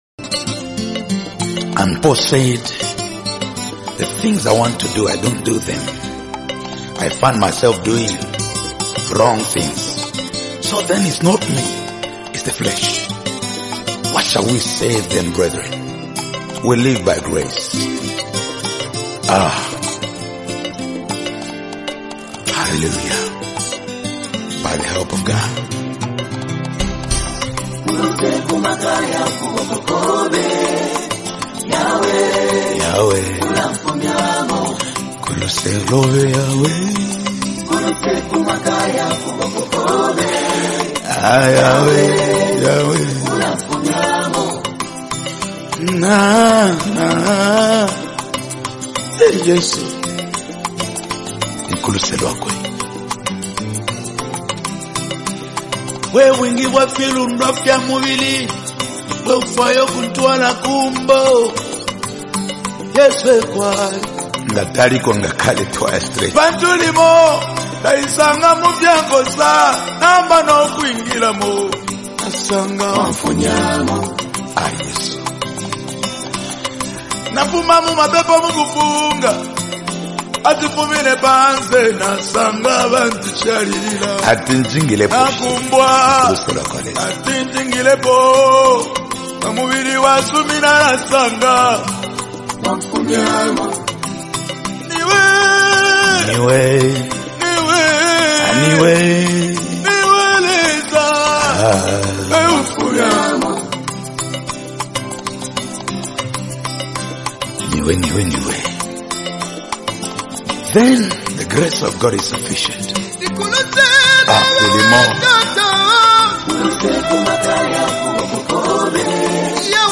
Latest Zambian Rumba Gospel Song 2025
vibrant rhythms and powerful lyrics
The infectious beats and uplifting message
traditional Rumba sounds